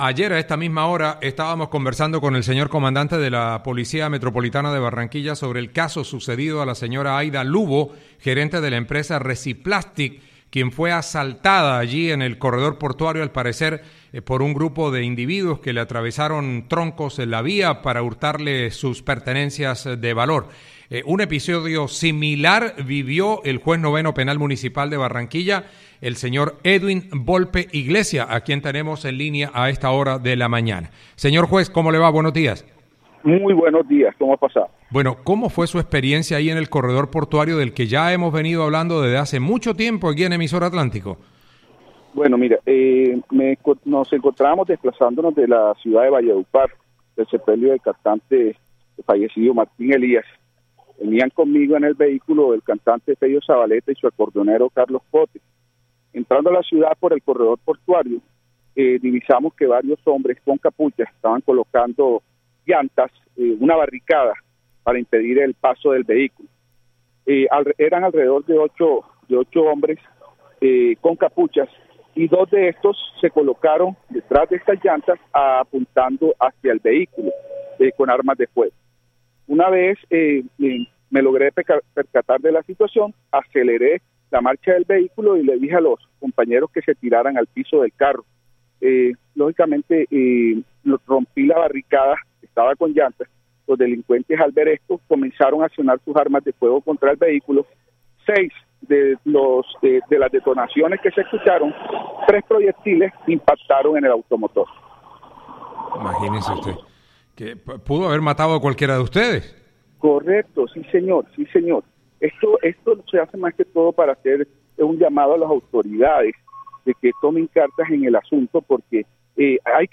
Así lo narró el funcionario judicial en diálogo con Atlántico en Noticias, al tiempo que hizo un llamado a las autoridades para que adopten las medidas necesarias para garantizar la seguridad en esta importante arteria vial.